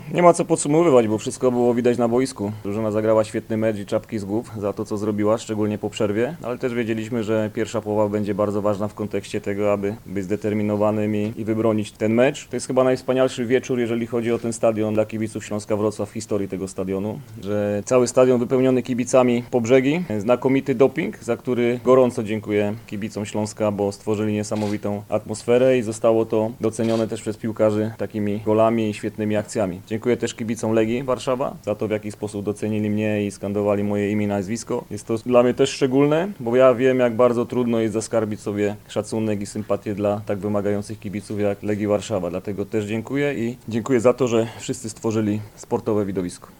Na pomeczowej konferencji prasowej trener Jacek Magiera skomentował występ swojej drużyny i podziękował kibicom obu drużyn.